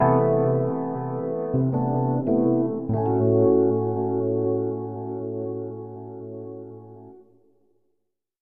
Sound effect for Club Nintendo Picross and Club Nintendo Picross+